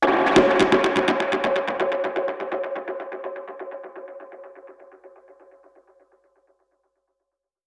Index of /musicradar/dub-percussion-samples/125bpm
DPFX_PercHit_B_125-01.wav